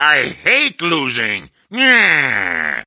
Wario vents his hatred for losing in Mario Kart Wii.
Wario_(Lose_2)_Mario_Kart_Wii.oga